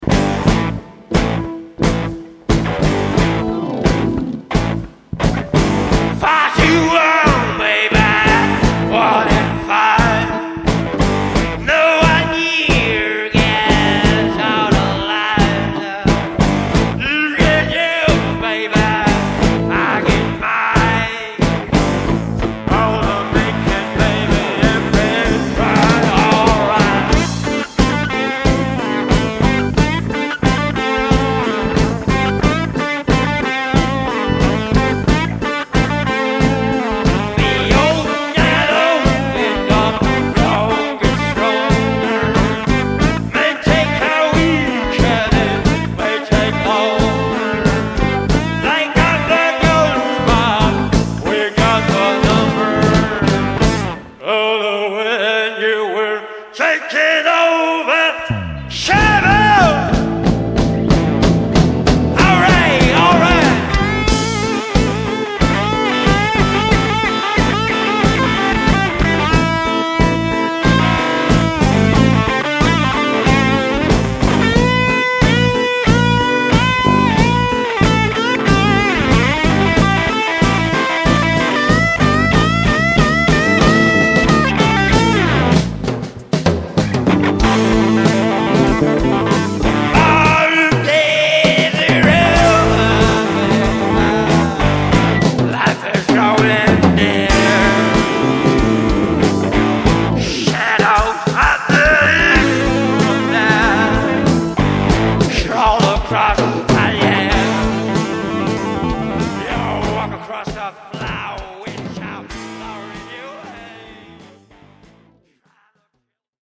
Older mp3s with bass guitar player